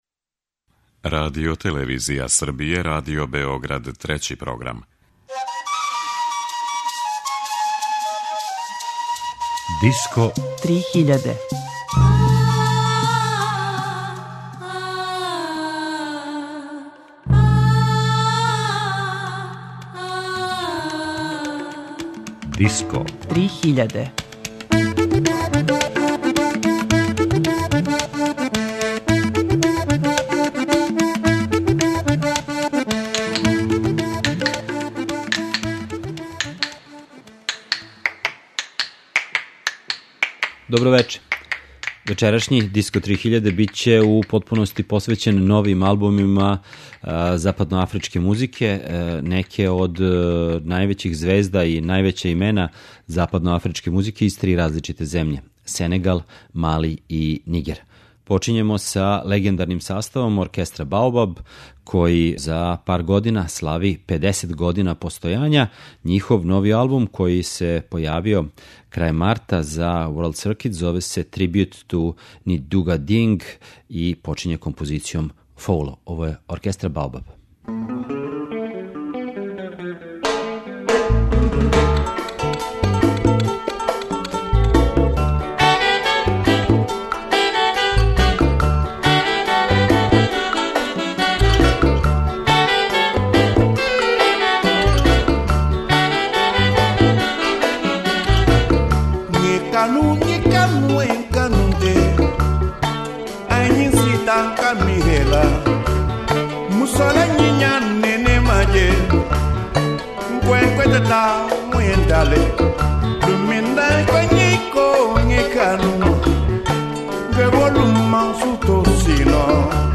гитариста и певач